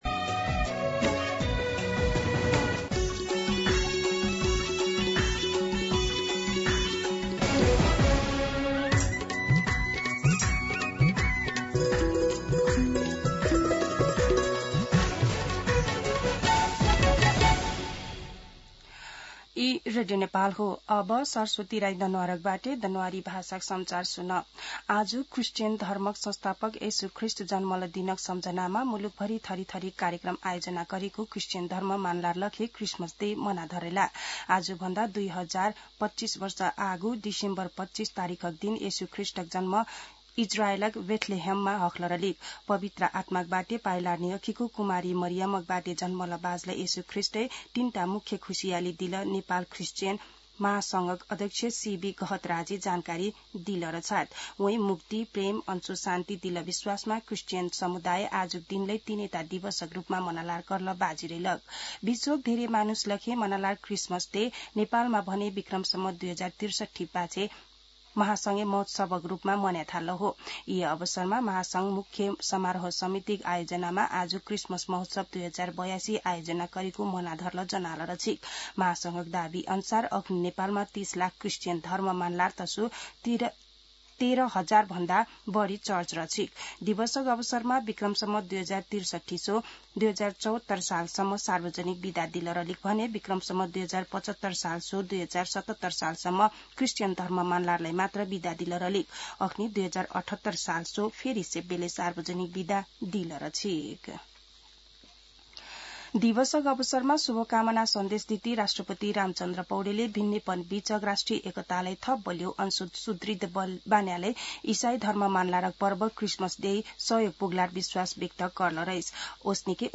दनुवार भाषामा समाचार : १० पुष , २०८२
Danuwar-i-News.mp3